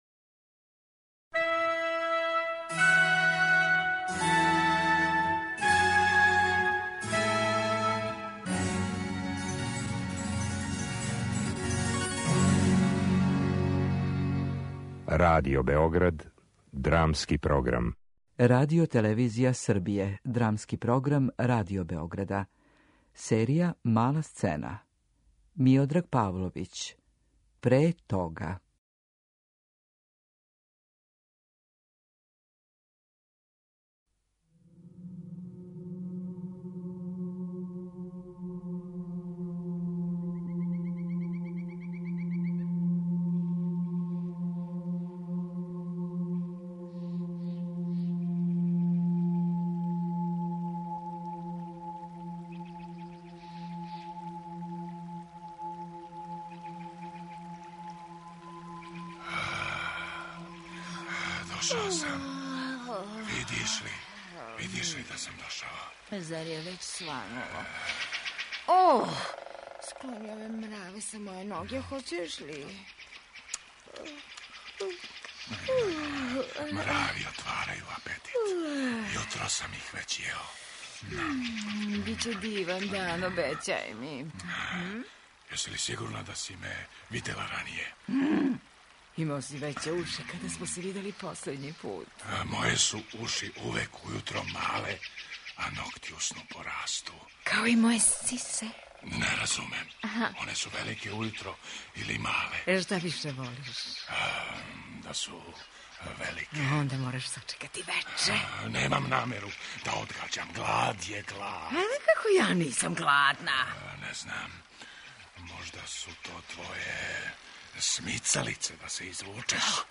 drama.mp3